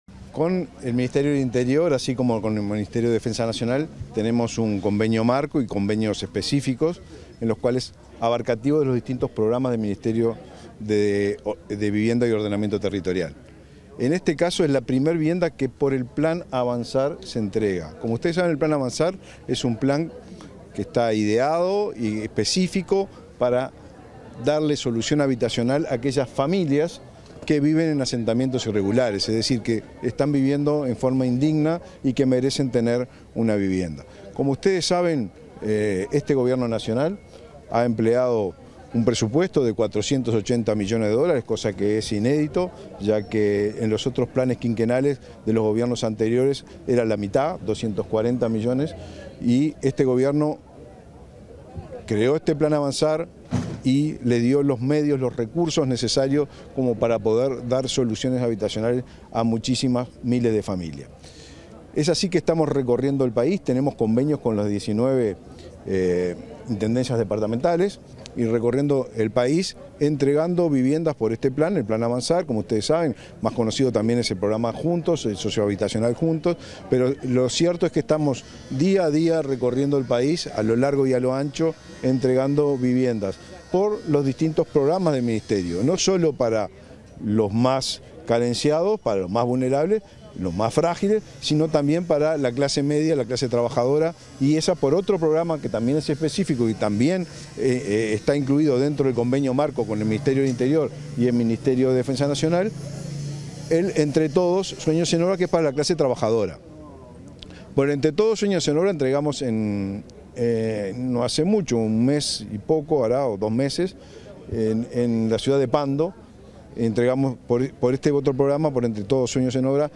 Declaraciones del ministro de Vivienda y Ordenamiento Territorial, Raúl Lozano
Declaraciones del ministro de Vivienda y Ordenamiento Territorial, Raúl Lozano 04/06/2024 Compartir Facebook X Copiar enlace WhatsApp LinkedIn Tras la entrega de la primera vivienda por convenio con el Ministerio del Interior, este 4 de junio, el ministro de Vivienda y Ordenamiento Territorial, Raúl Lozano, realizó declaraciones a la prensa.
lozano prensa.mp3